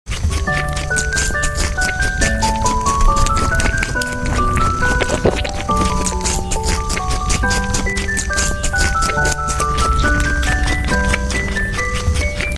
Bunny cute sound effects free download